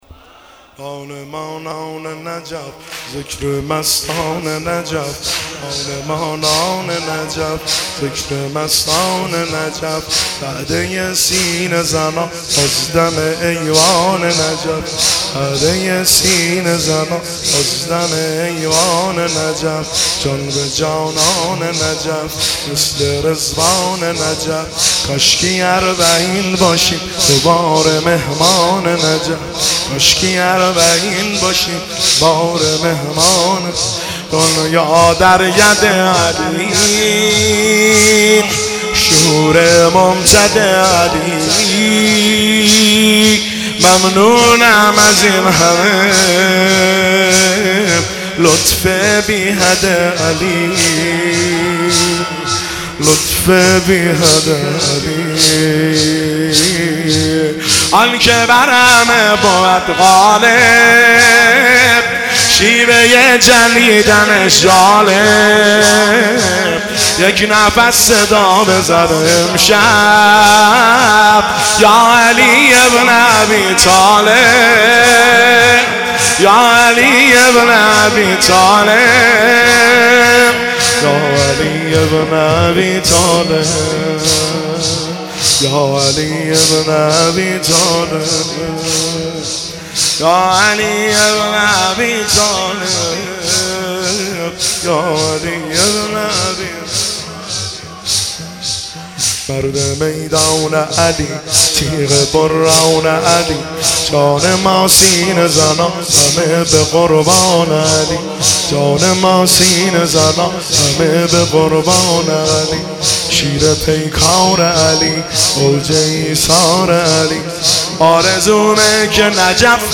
نان ما نان نجف ذکر مستانه نجف - دانلود صوت مداحی تک